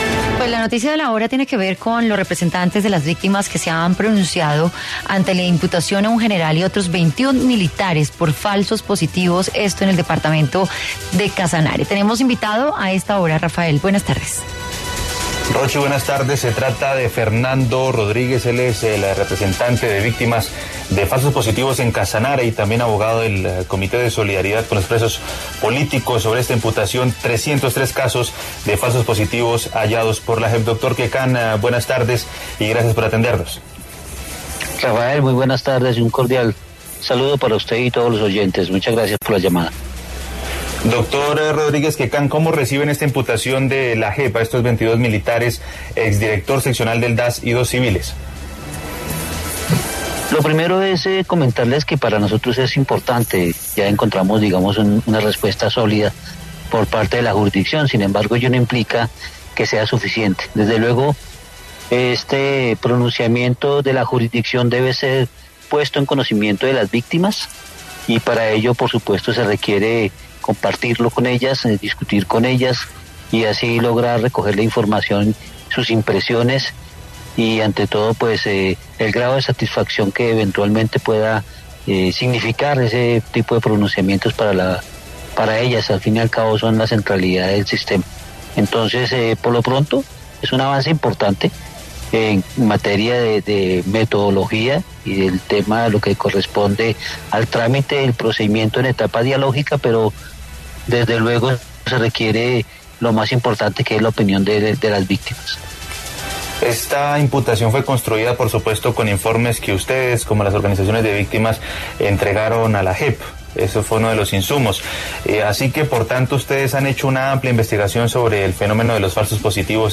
En entrevista con Contrarreloj